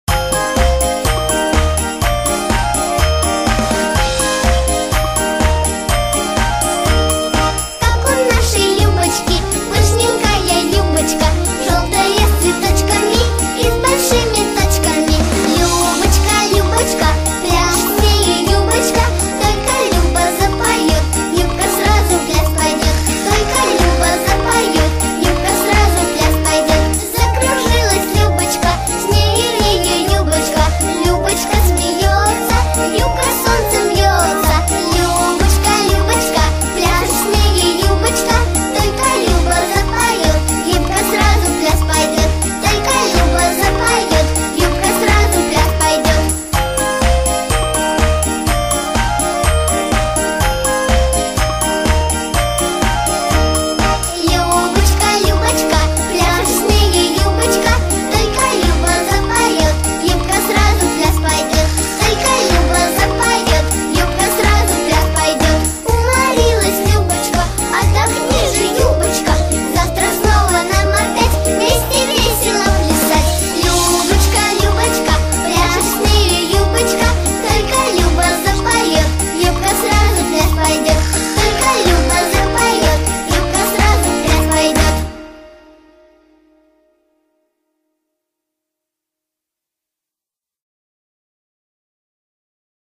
• Категория: Детские песни
народный мотив, пляска, плясовая